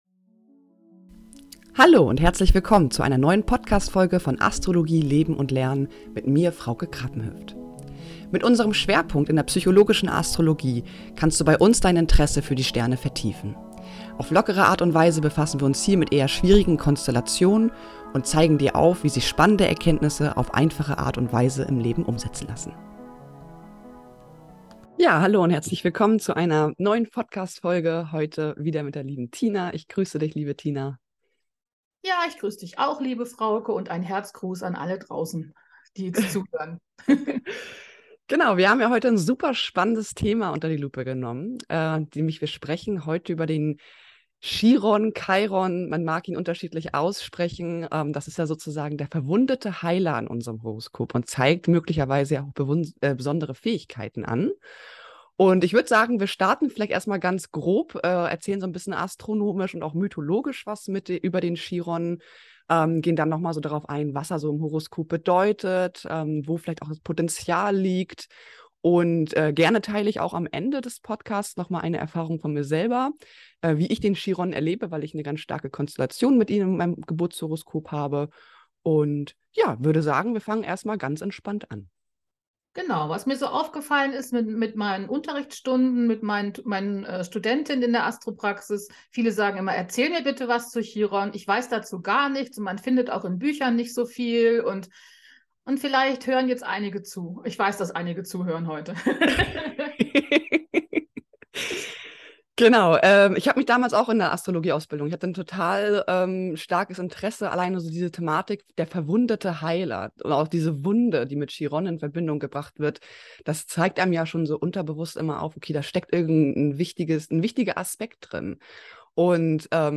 Text und Sprecherinnen